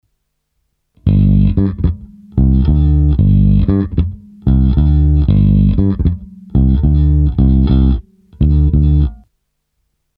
Nejdřív jen stejné kolečko samotného tracku basy:
Music Man StingRay